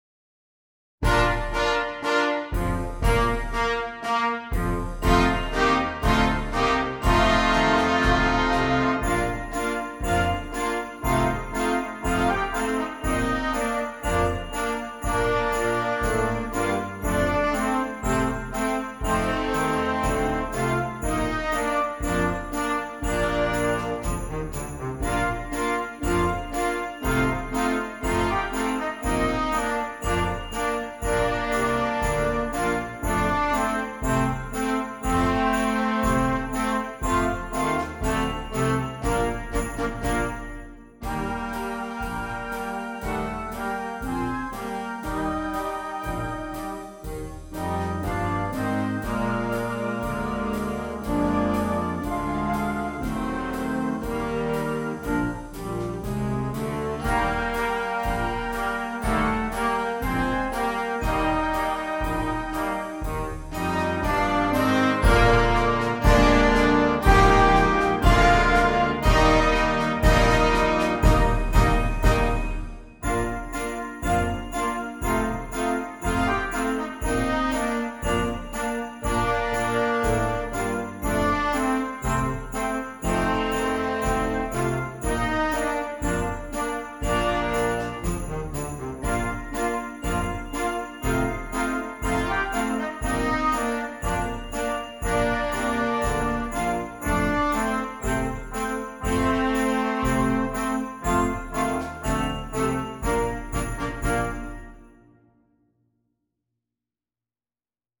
Concert Band
Traditional